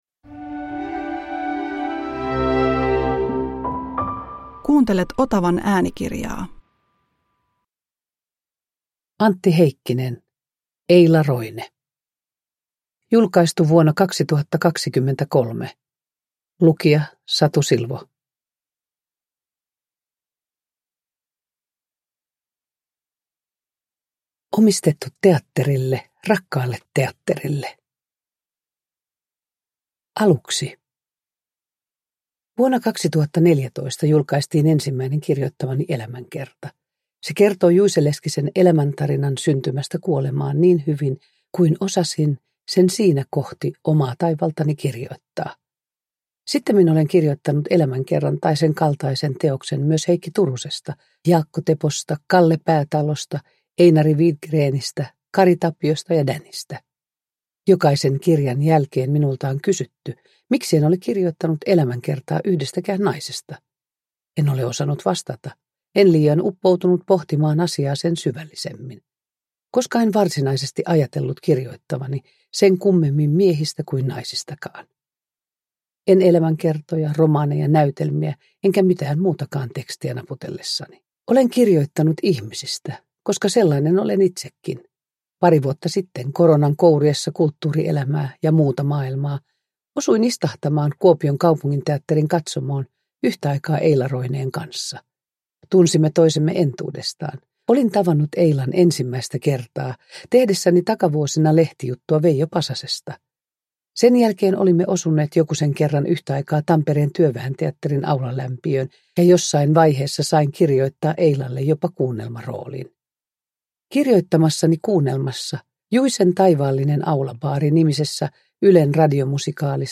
Eila Roine – Ljudbok – Laddas ner
Uppläsare: Satu Silvo